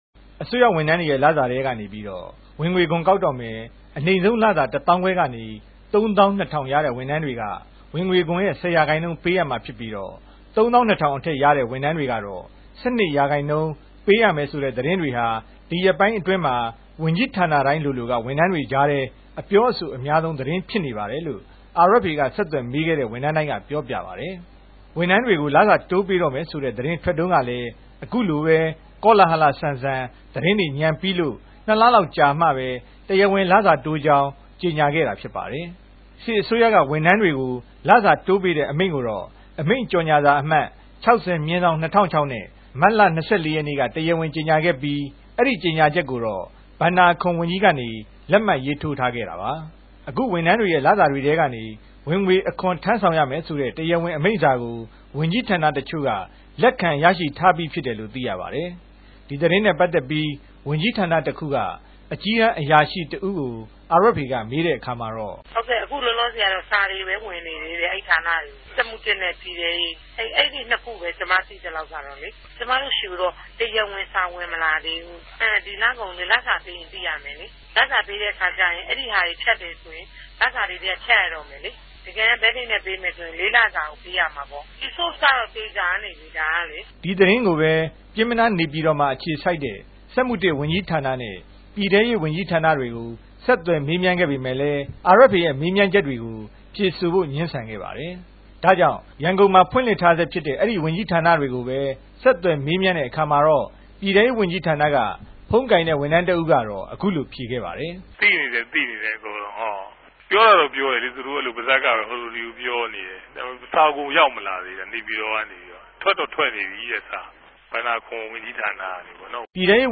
အစိုးရဝန်ထမ်းတေကြို ဆက်သြယ်မေးူမန်း္ဘပီး သတင်းပေးပိုႛထားတာကို နားထောငိံိုင်ပၝတယ်။